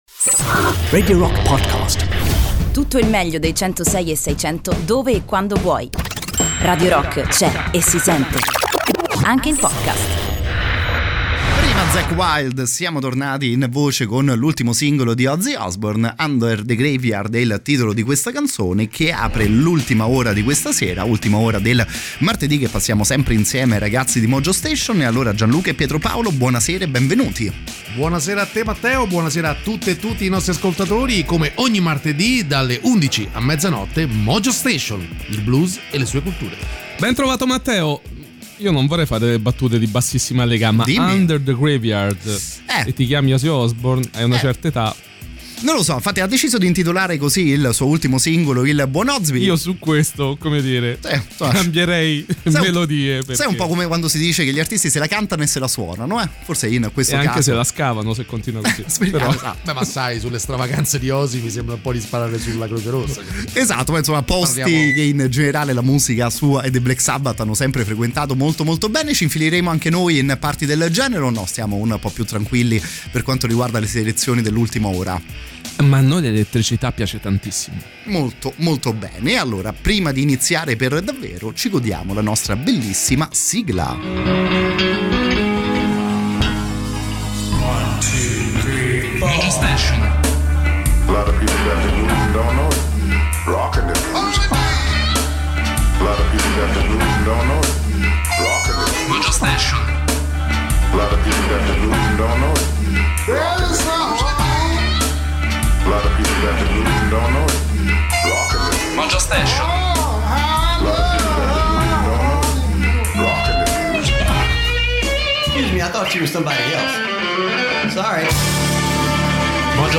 In diretta sui 106e6 di Radio Rock ogni martedì dalle 23:00